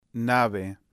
Ícone azul de alto-falante indicando volume ou som contém quatro segmentos